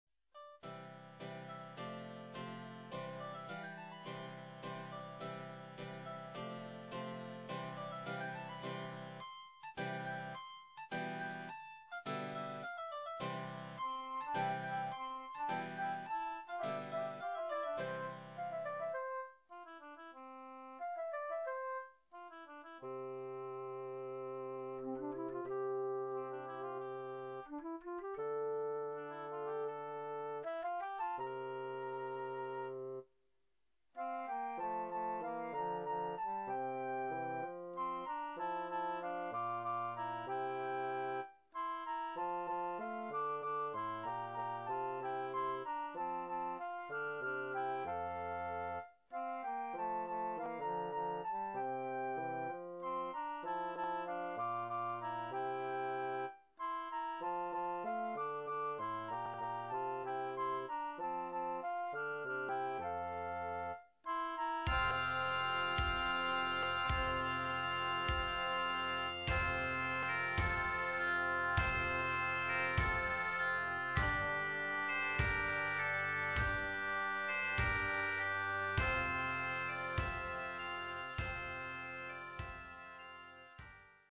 Everything is made in MIDI which gives a thinner sound.
interrupted by Jack's father [instr.]